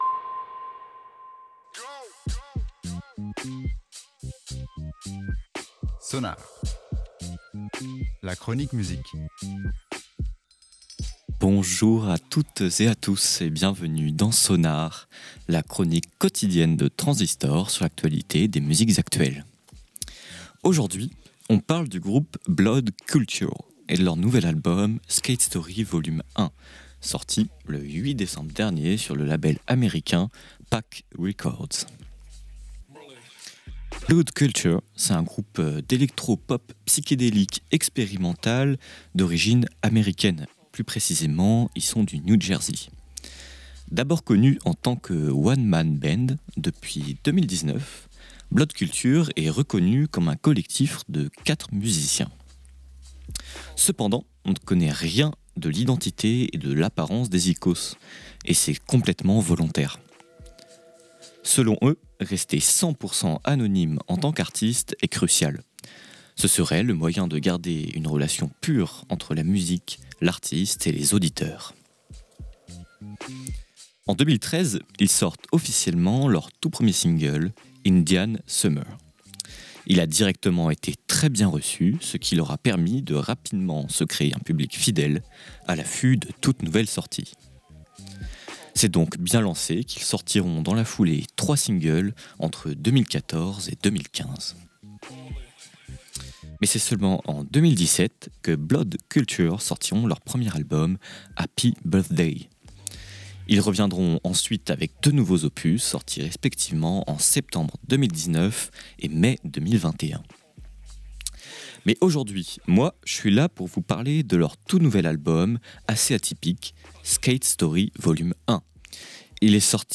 chillwave